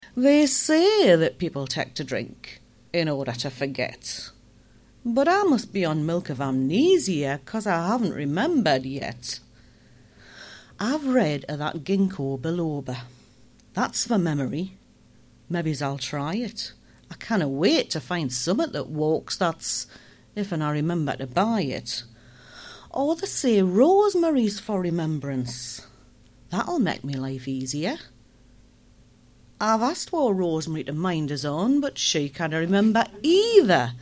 poem
It's all at Ashington Folk Club!